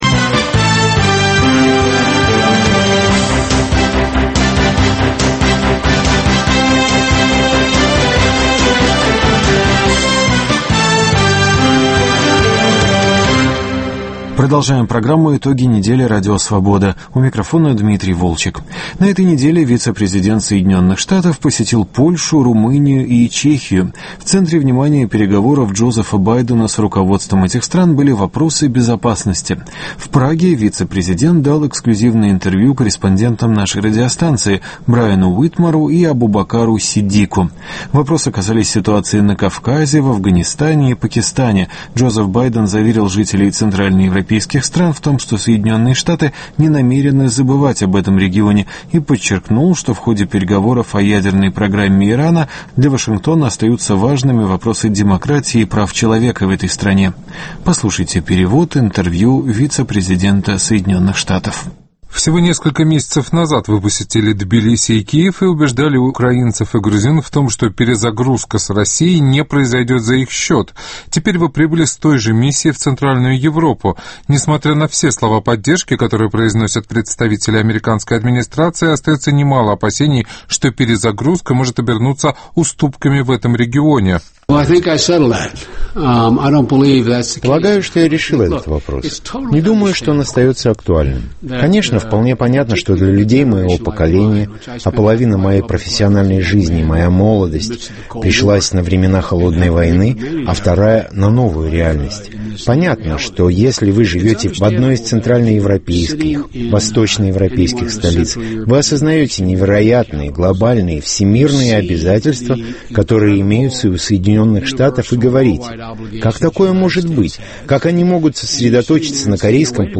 Интервью вице-президента США Джозефа Байдена и обсуждение итогов его поездки в Европу, обзоры еженедельников и блогов, арт-хроника